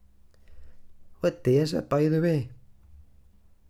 glaswegian_audio
glaswegian
scottish